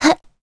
Gremory-Vox_Jump.wav